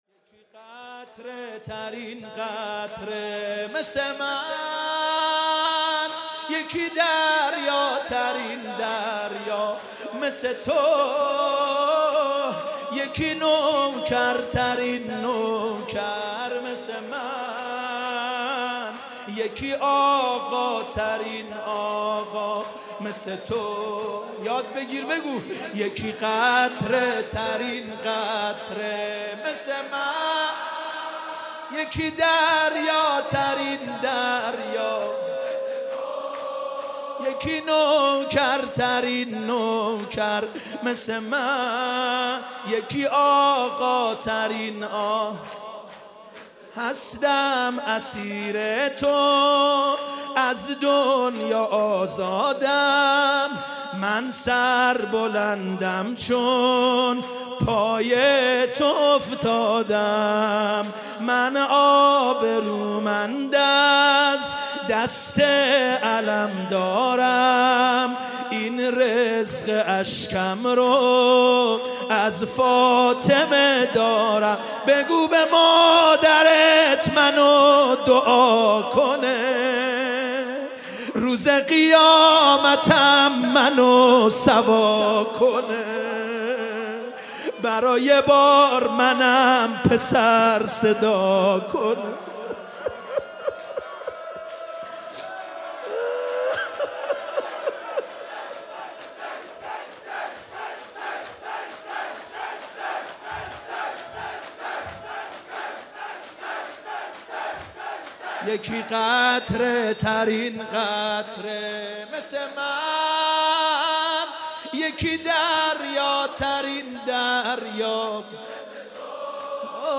شب اول محرم سال 95/هیت رزمندگان اسلام